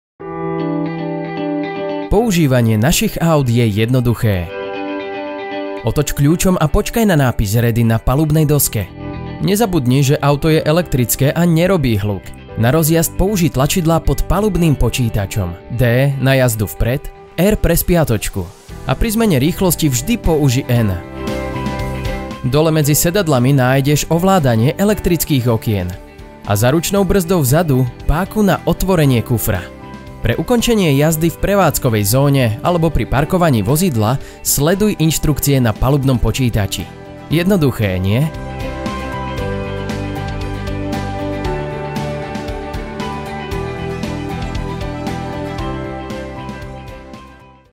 Native speaker Male 20-30 lat
Demo lektorskie
Spot reklamowy